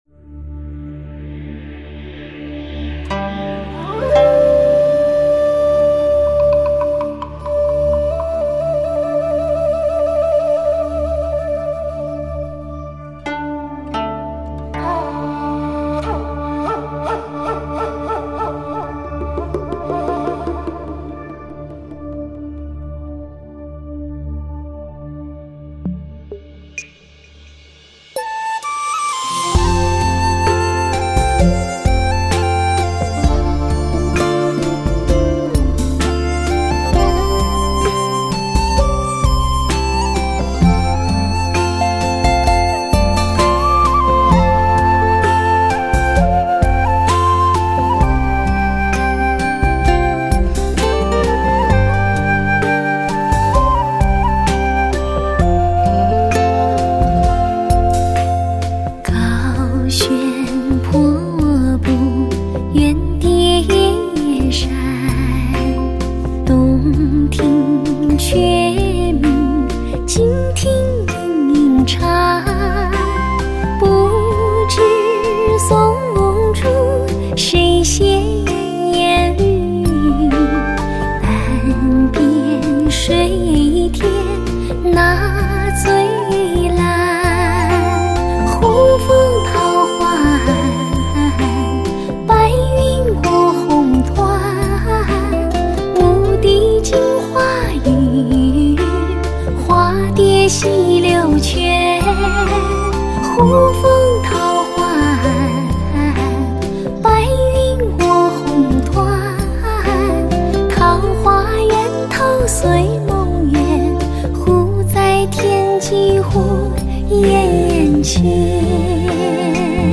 唱片类型：流行音乐
音乐画面丰满，富于动感和人性，
节奏摩登，真诚感情，真实演绎，完美录音！
低品质试听